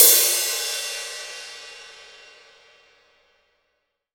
Index of /90_sSampleCDs/AKAI S6000 CD-ROM - Volume 3/Crash_Cymbal1/16-17_INCH_CRASH